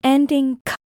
Spellings-of-Ending-k-duck-honk-phoneme-name-AI.mp3